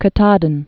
(kə-tädn)